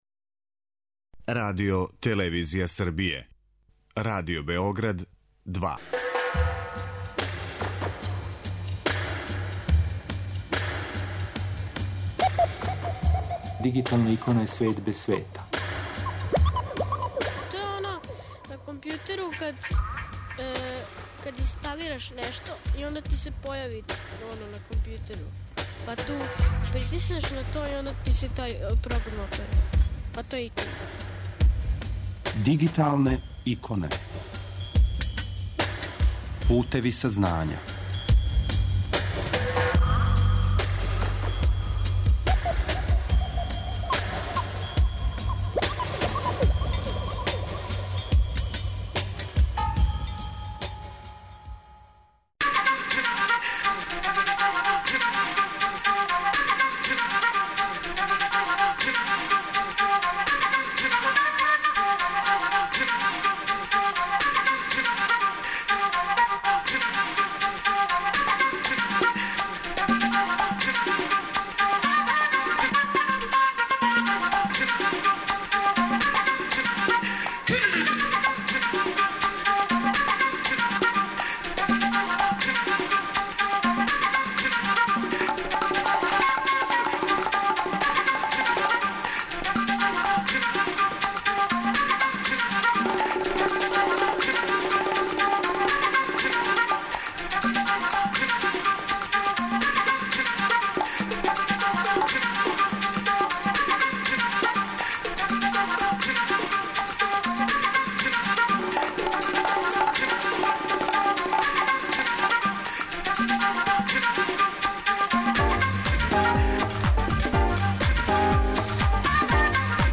У данашњој емисији настављамо са емитовањем разговора које смо забележили на 21. фестивалу информатичких достигнућа, ИНФОФЕСТ-у, једном од највећих ИТ догађаја у региону.